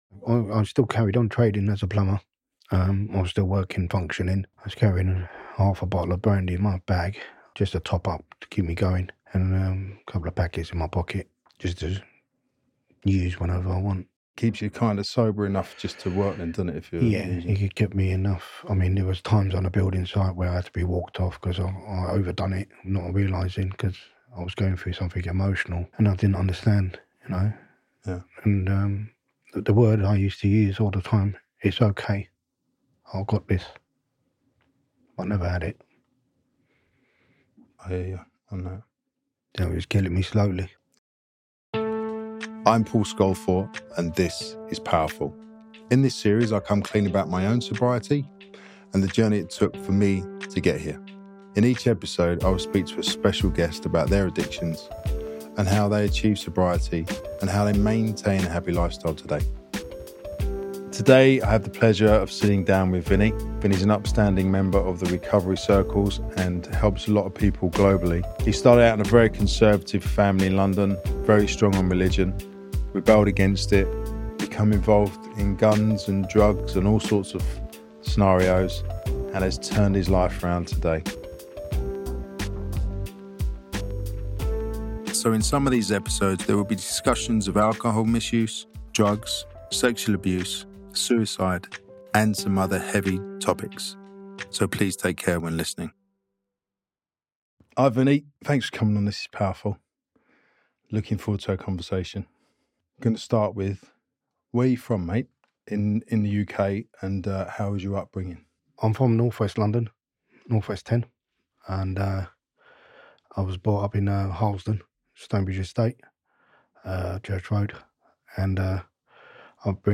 This is one of those remarkable interviews that will surprise you, pull on your heart strings and reveal the depth of the human spirit.